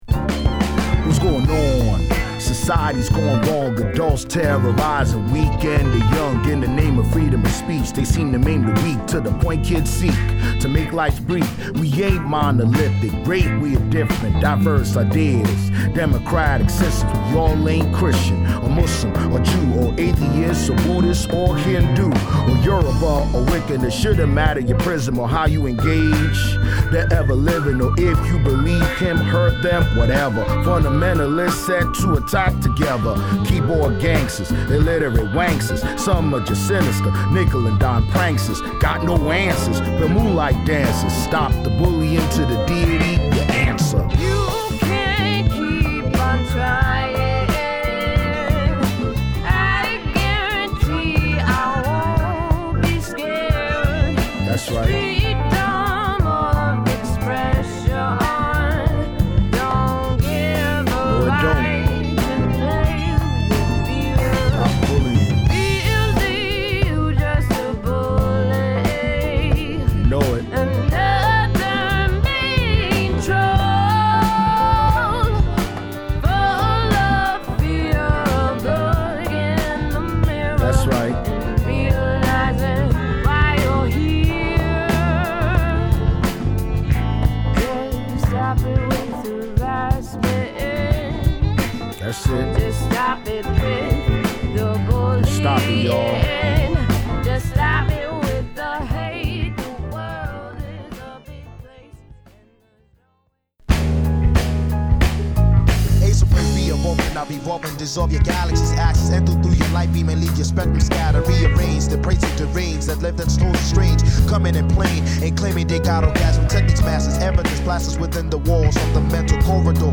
LP)Hip Hop / R&B